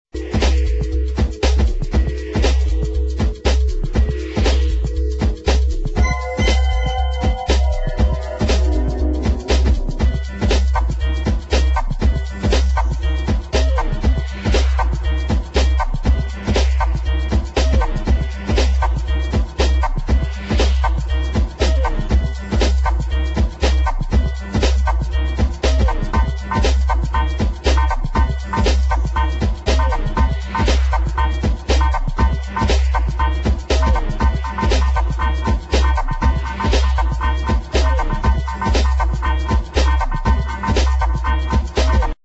elektronische Musik